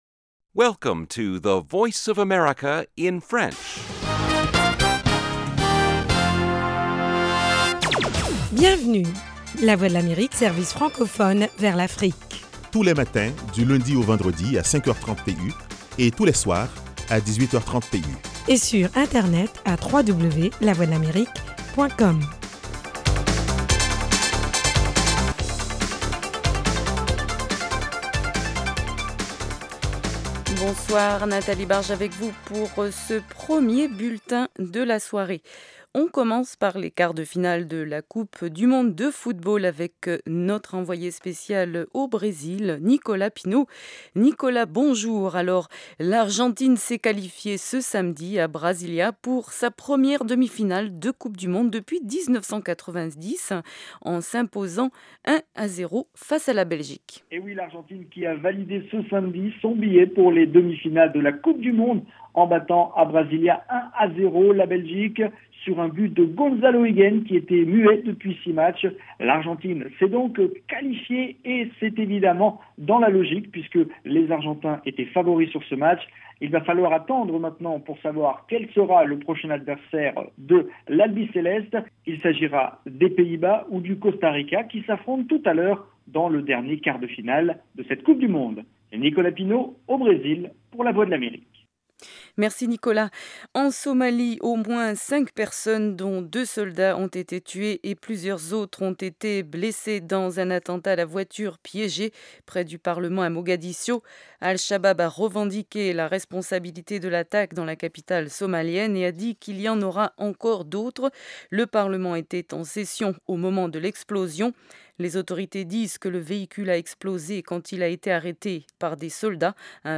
Le magazine vous tient aussi au courant des dernières découvertes en matière de technologie et de recherche médicale. Carrefour Santé et Sciences vous propose aussi des reportages sur le terrain concernant les maladies endémiques du continent : paludisme, sida, polio, grippe aviaire…